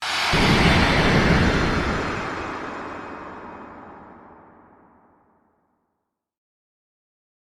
Wind Strikes
Wind Strikes is a free nature sound effect available for download in MP3 format.
Wind Strikes.mp3